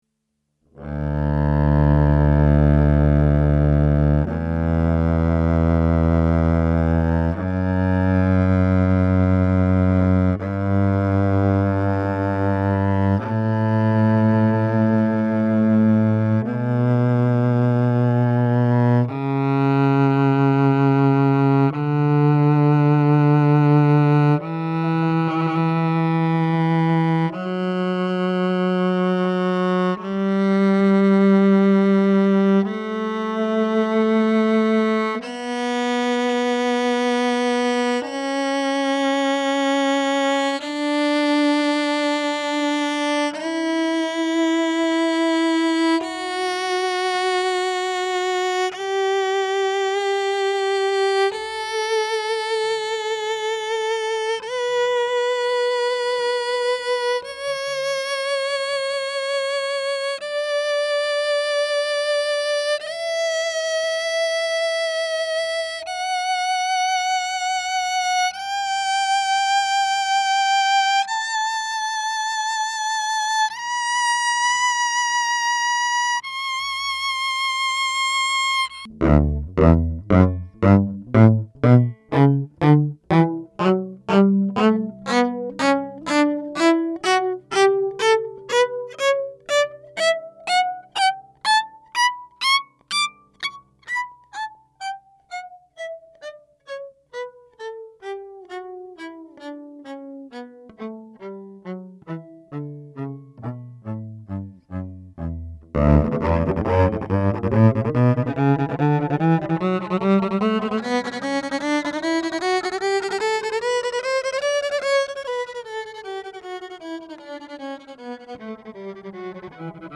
A StringAmp™ Cello system has a warm deep sound, both as a solidbody and an acoustic, but in the acoustic, the Cello body is enhancing  the sound from the StringAmp lineout
in a very pleasing way.
StringAmpCelloSound.mp3